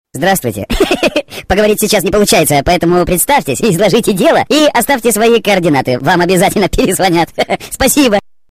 На автоответчик [10]